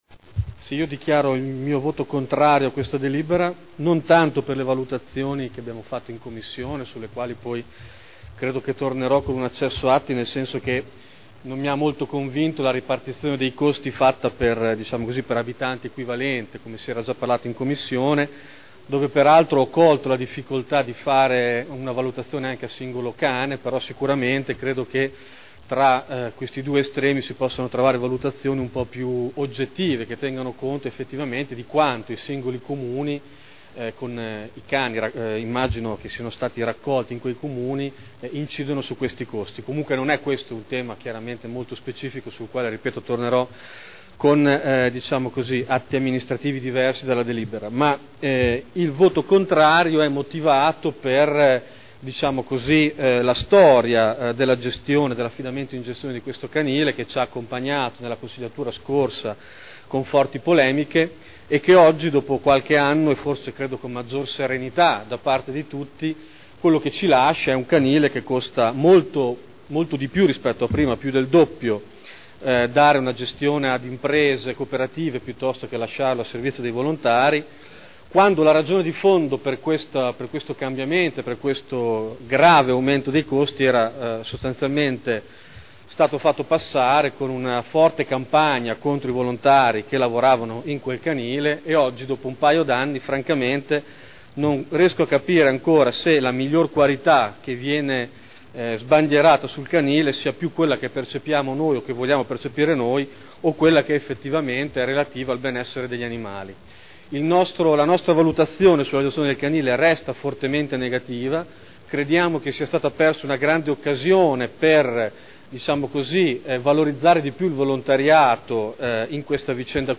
Davide Torrini — Sito Audio Consiglio Comunale
Dichiarazione di voto. Convenzione tra il Comune di Modena e i Comuni di: Bastiglia, Bomporto, Campogalliano, Castelfranco Emilia, Castelnuovo Rangone, Nonantola, Ravarino, San Cesario sul Panaro, Soliera, per il servizio di gestione del canile intercomunale di Modena e per attività veterinarie non istituzionali (Commissione consiliare del 9 febbraio 2010)